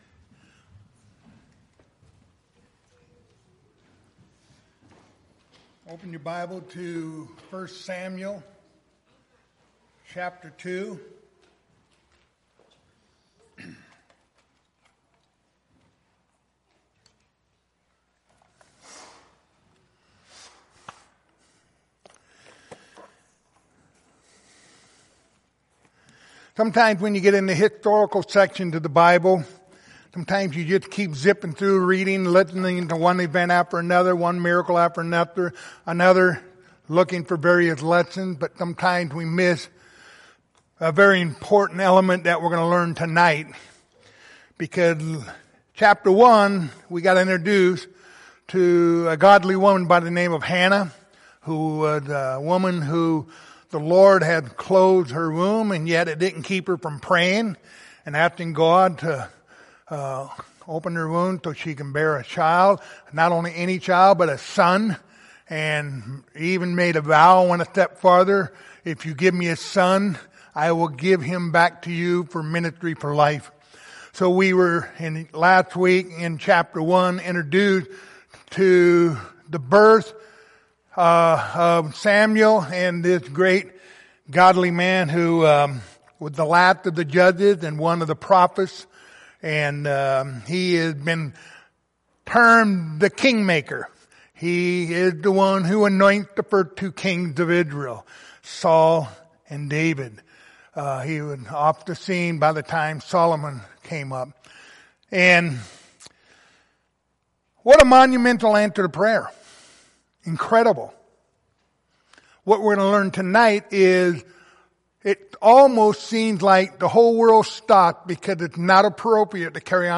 Passage: 1 Samuel 2:1-10 Service Type: Wednesday Evening